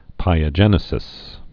(pīə-jĕnĭ-sĭs)